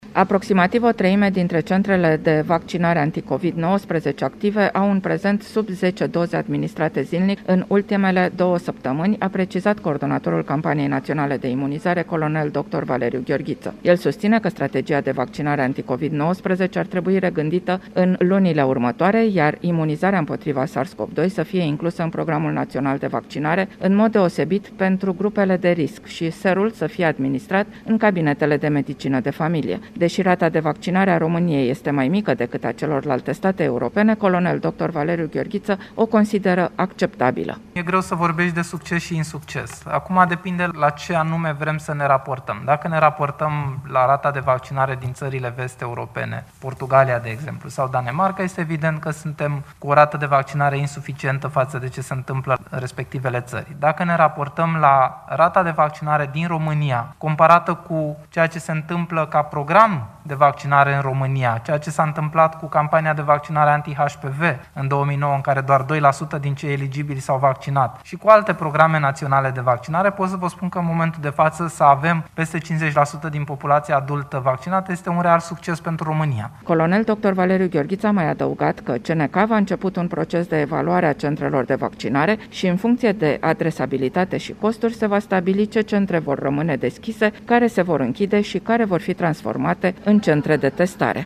În condiţiile în care în aproximativ o treime din centre se administrează mai puţin de 10 doze pe zi, coordonatorul campaniei nationale de vaccinare, doctorul Valeriu Gheorghiţă vorbeşte acum despre o regîndire a strategiei de vaccinare